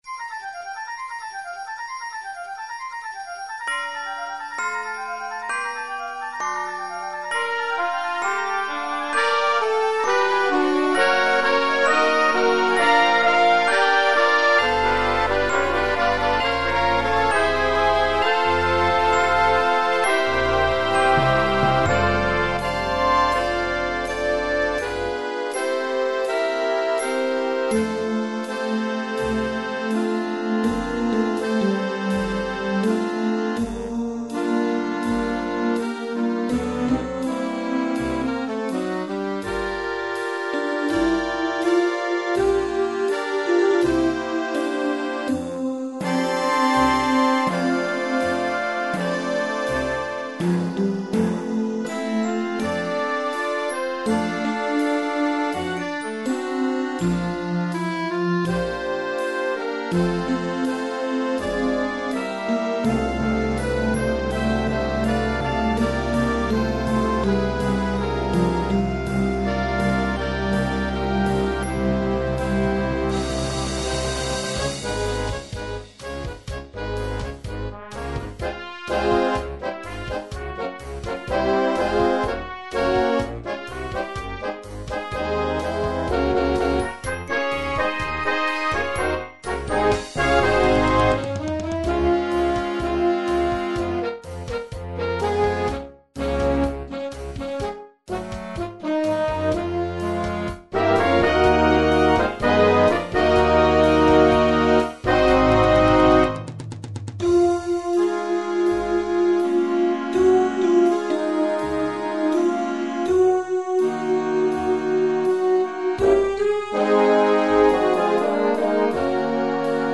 per voce solista (ad lib.) e banda
In questo medley dallo spiccato carattere jazz e rock